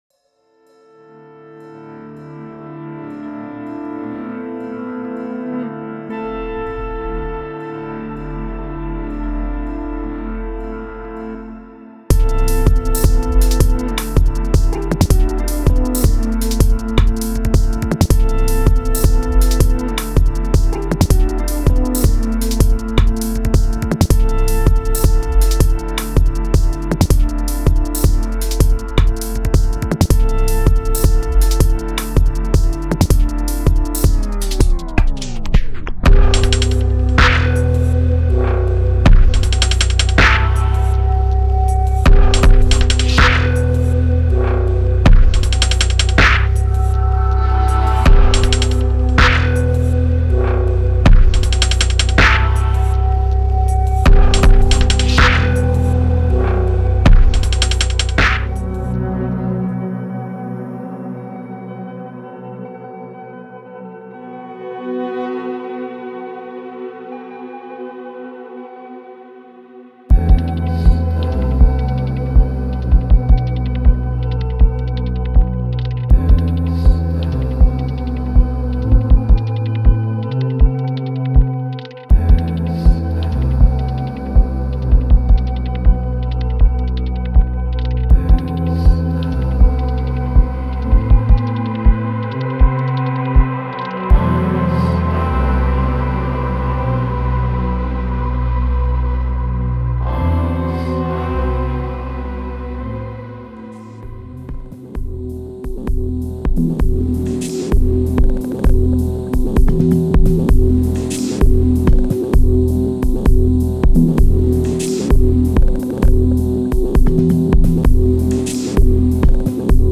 130 – 160 BPM